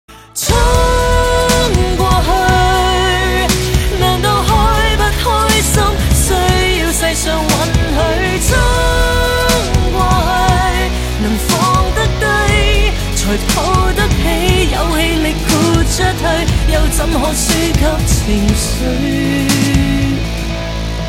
M4R铃声, MP3铃声, 华语歌曲 66 首发日期：2018-05-14 10:39 星期一